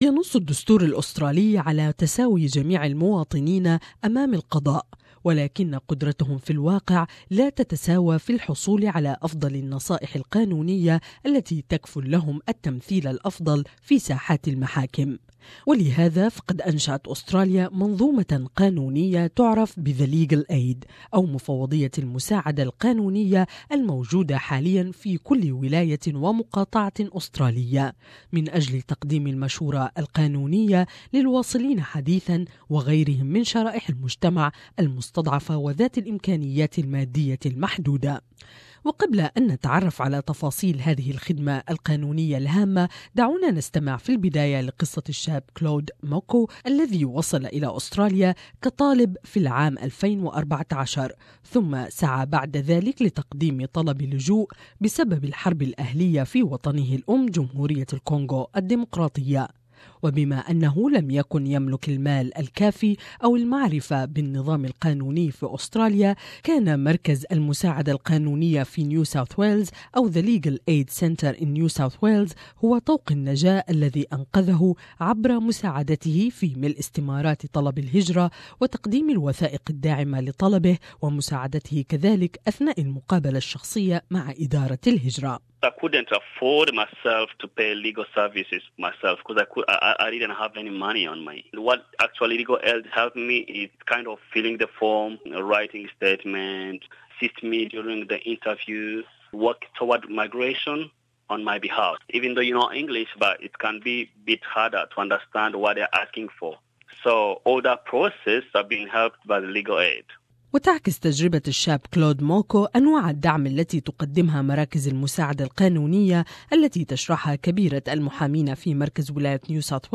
In order to close this gap, there are eight legal aid commissions in Australia, one in each state and territory. The purpose of legal aid commissions is to provide vulnerable, disadvantaged and newly arrived Australians with access to justice. More in this report